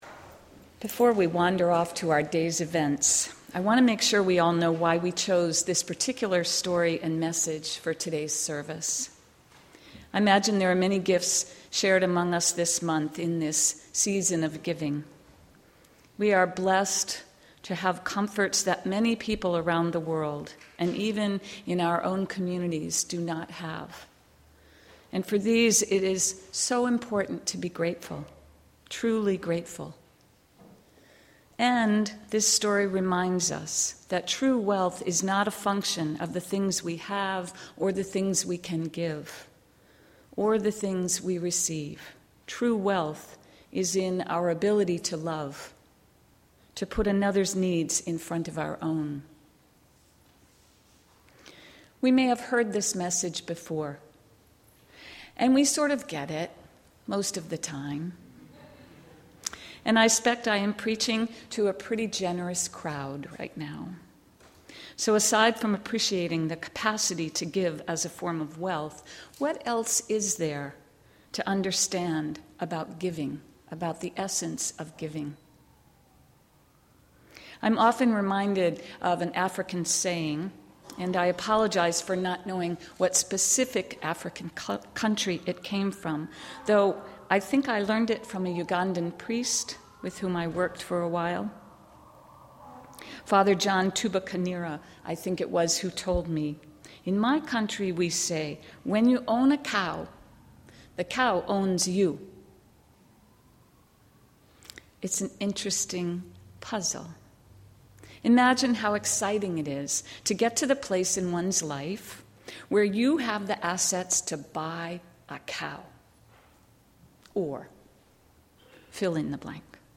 Our mostly multi-generational service features our younger population, sharing the wisdom of Jeff Boudreau’s The Quiltmaker’s Gift, while giving us time to appreciate the earth’s turning and some additional hints at gift essence, as well. We come together for a special morning of returning light, music, and joy.